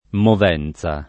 movenza